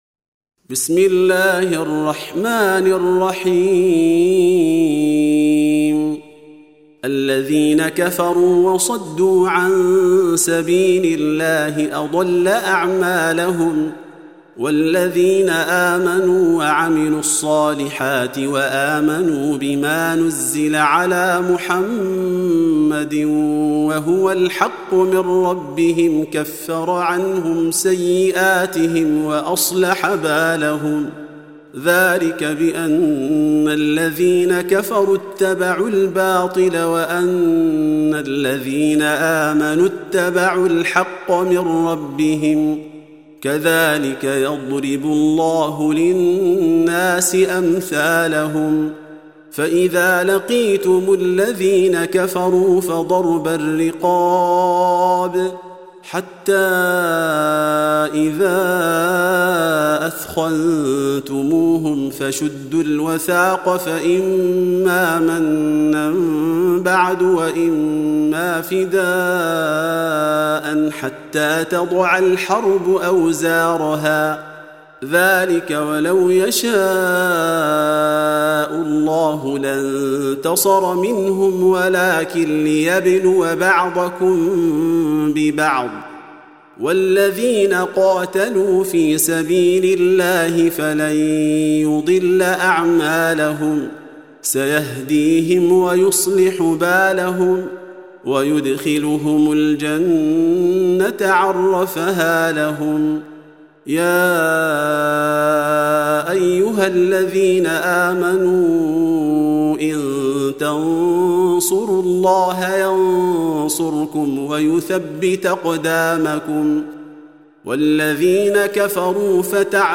Surah Repeating تكرار السورة Download Surah حمّل السورة Reciting Murattalah Audio for 47.